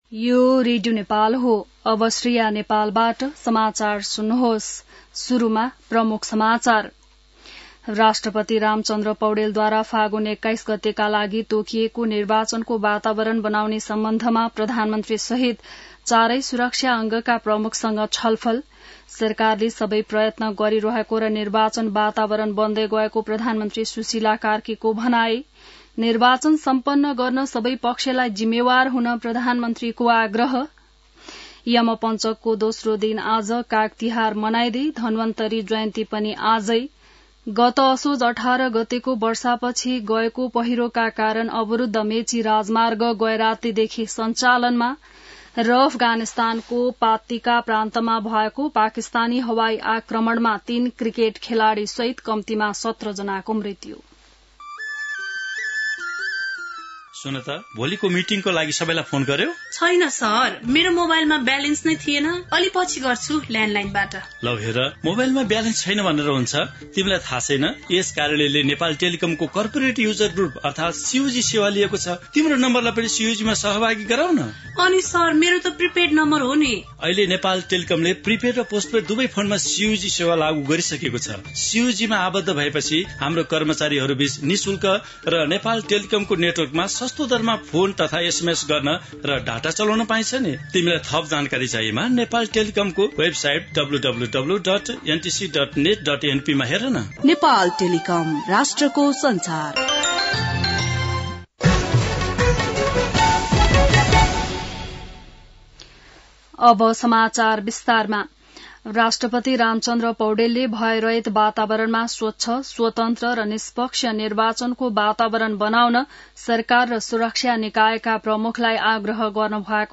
बिहान ७ बजेको नेपाली समाचार : २ कार्तिक , २०८२